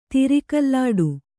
♪ tiri kallāḍu